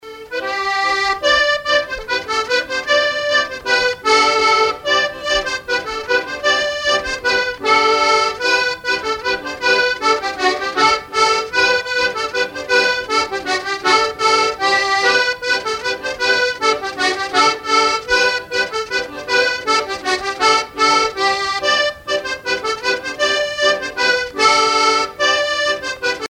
Ridée à l'accordéon
danse : laridé, ridée
Chansons traditionnelles